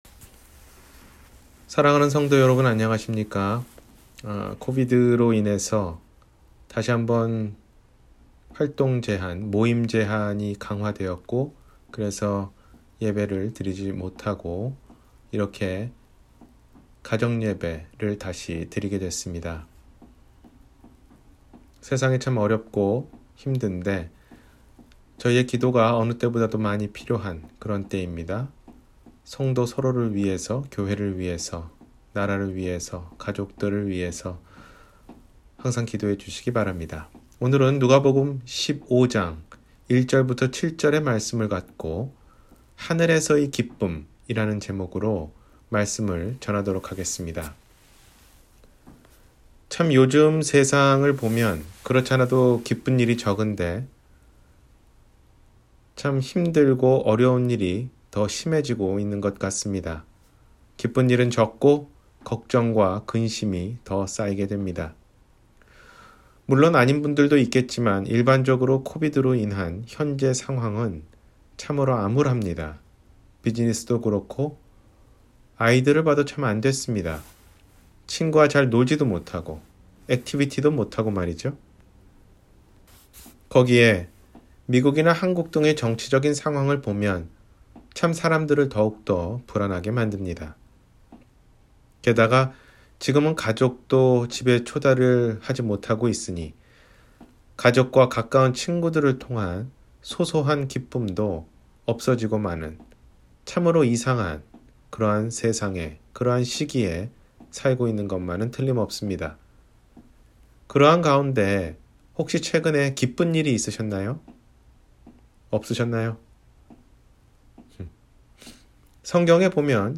하늘에서의 기쁨 – 주일설교